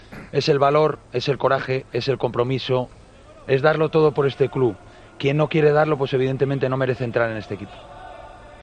El entrenador del Alavés fue preguntado en sala de prensa por la ausencia de Lucas Pérez en la convocatoria ante el Atlético de Madrid.